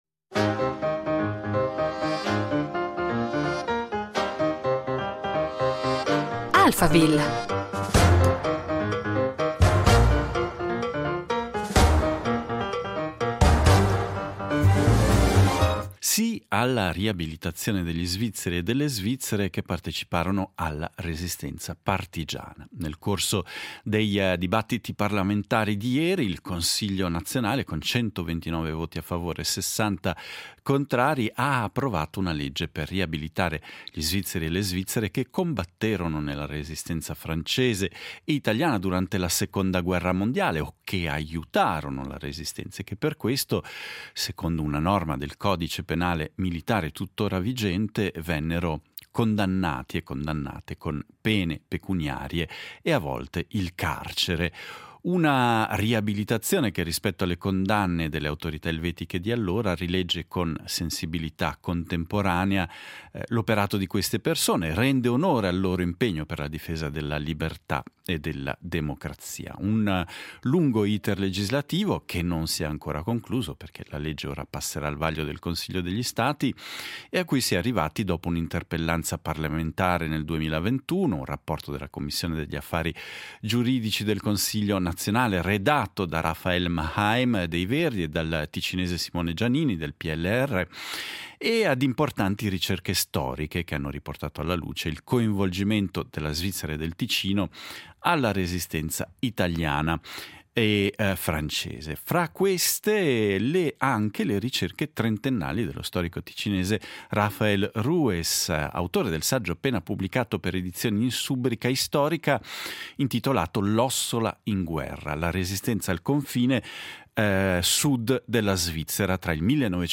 Ne abbiamo parlato con lo storico ticinese